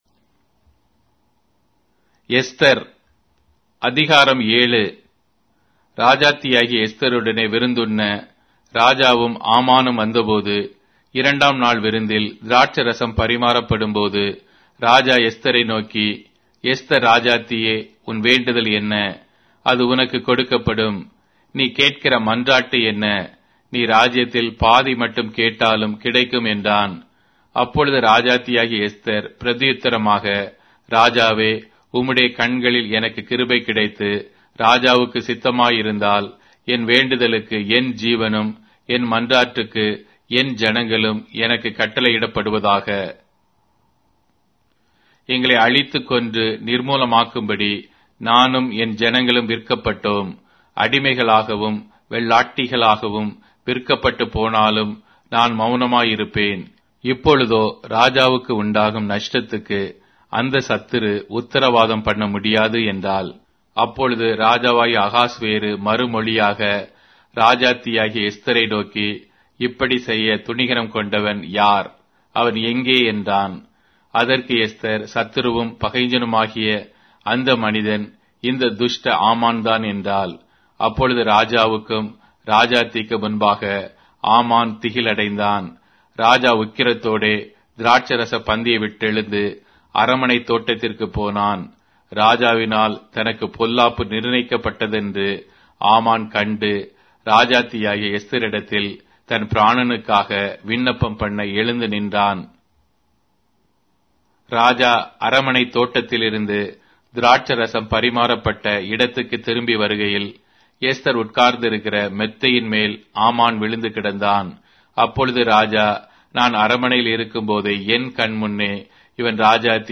Tamil Audio Bible - Esther 3 in Mhb bible version